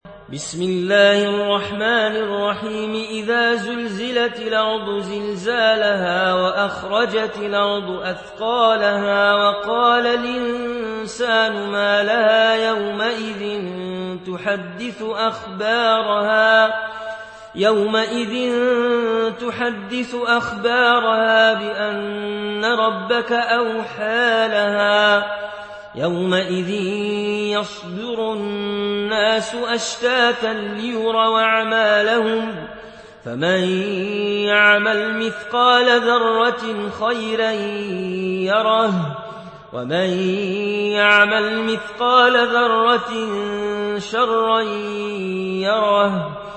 برواية ورش عن نافع